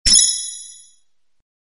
CollectGem.ogg